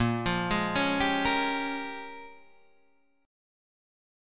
Bbm13 chord